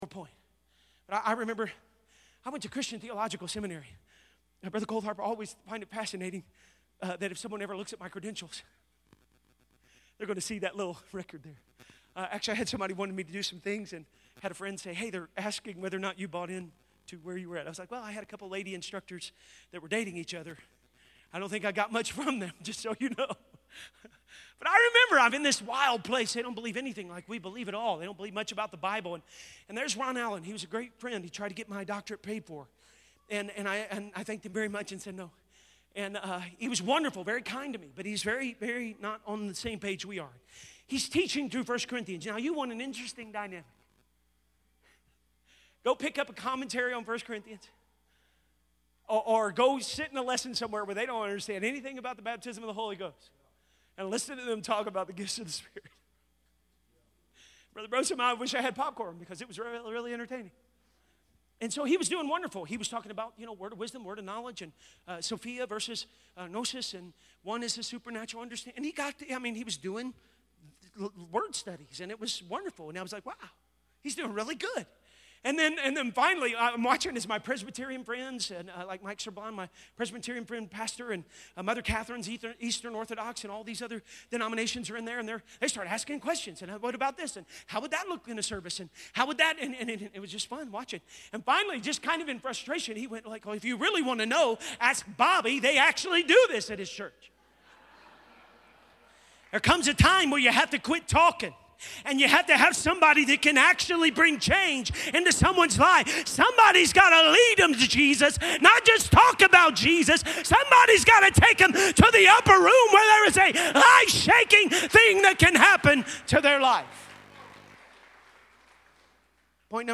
Sermon Archive | Illinois District